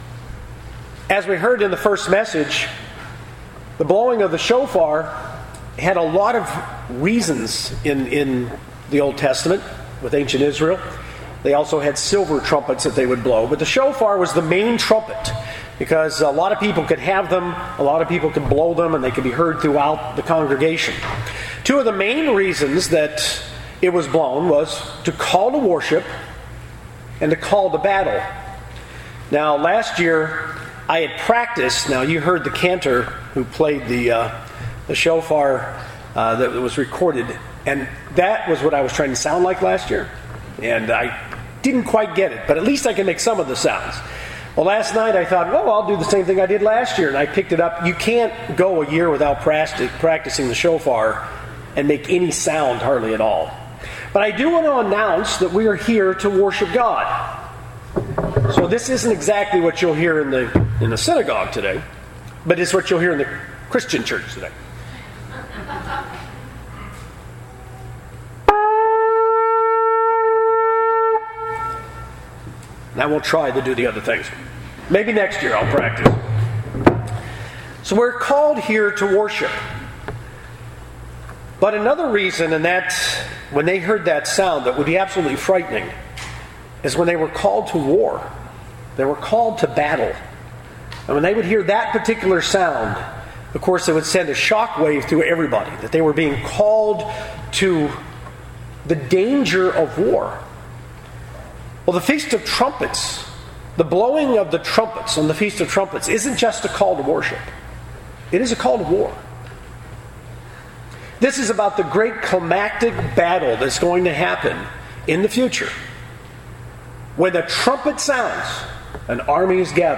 The book of Revelation prophesies that there will be seven trumpets that will sound leading up to Jesus Christ's return, each symbolically describing events that will take place. This is an overview sermon, covering the Church's understanding of those symbols of future events.